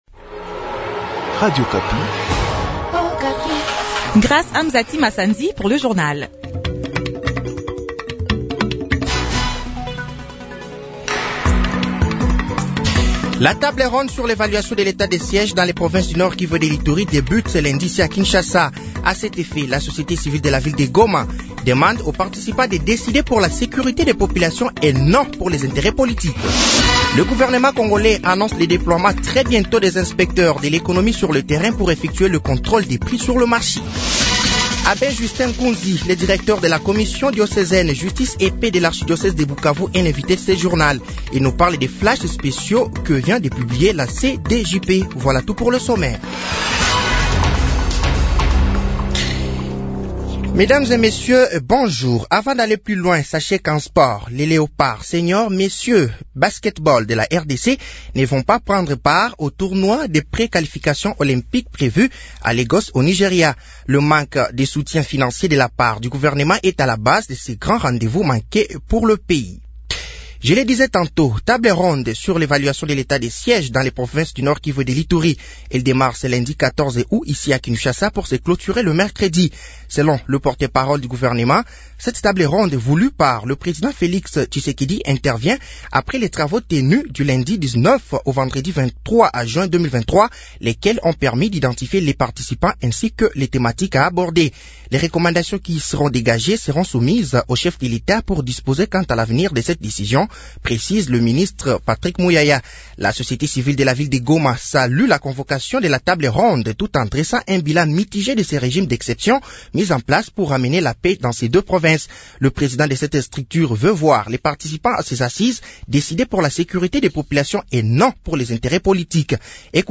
Journal français de 6h de ce lundi 14 août 2023